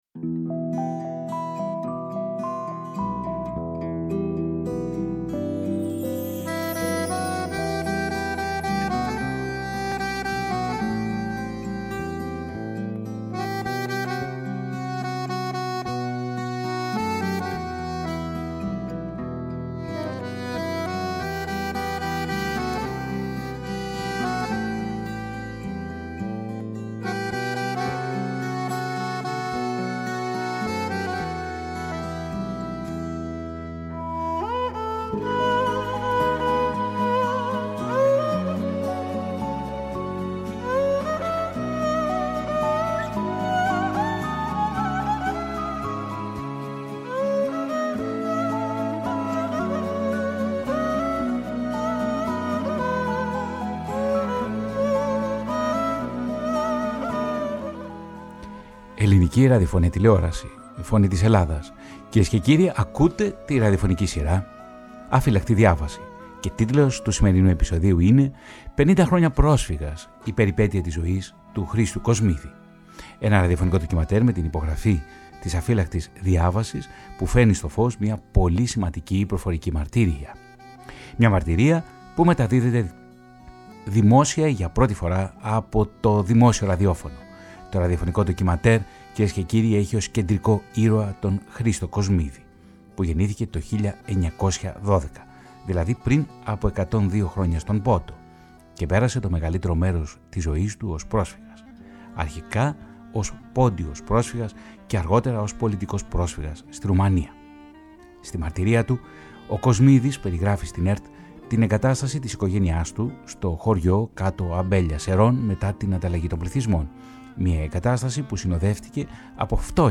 Μία πολύ σημαντική προφορική μαρτυρία φέρνει στο φως η ραδιοφωνική σειρά “Αφύλαχτη Διάβαση”, μία μαρτυρία που μεταδόθηκε δημόσια για πρώτη φορά από τη Φωνή της Ελλάδας. Το ραδιοφωνικό ντοκιμαντέρ μεταδόθηκε την Παρασκευή 6 Δεκεμβρίου 2024.